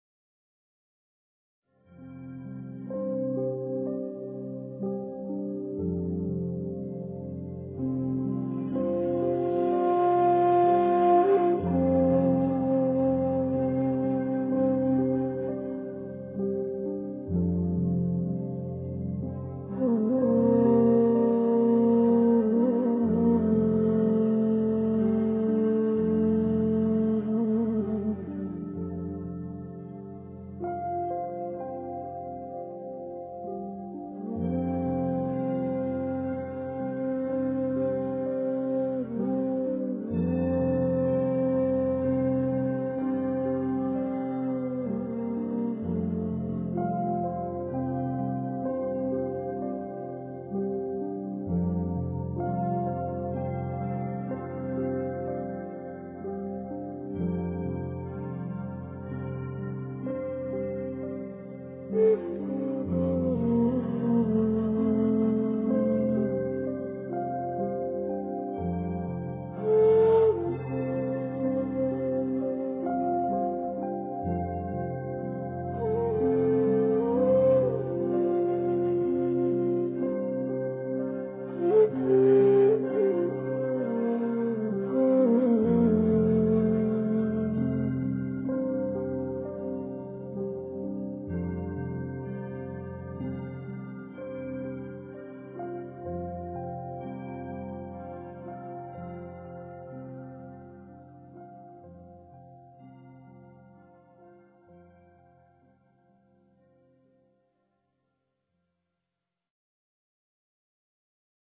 夜宁静Tranquil Night--瑜伽静心曲